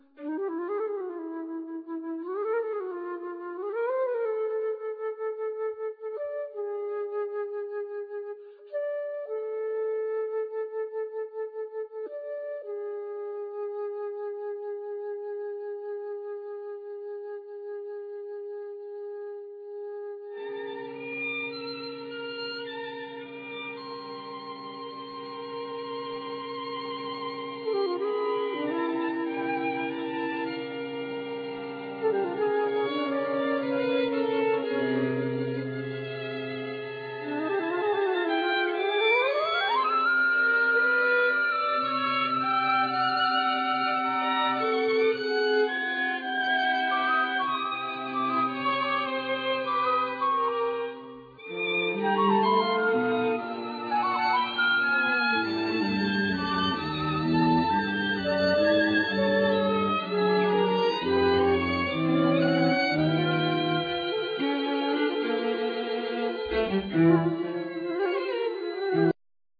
Piano
Drums,Vibraphone
Soprano,Alto,Tenor,Bass
1st Violin,2nd Violin,Viola,Cello